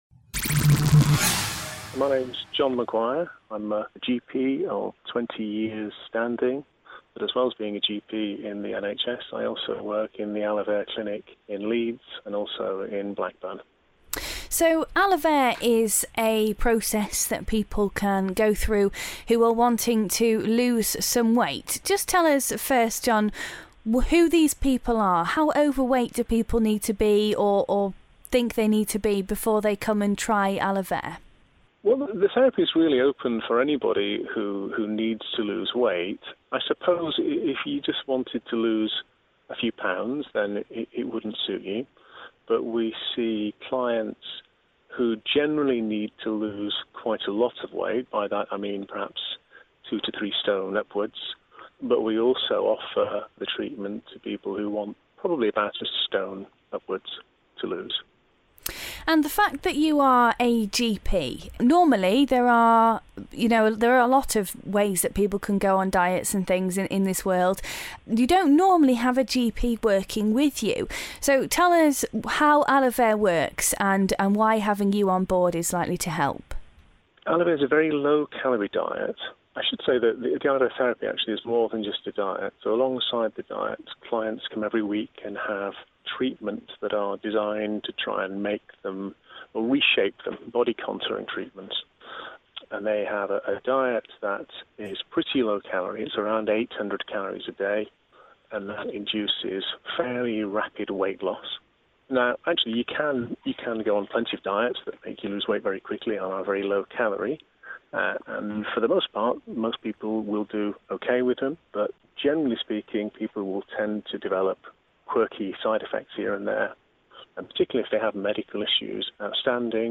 A GP tells us about a programme that could help!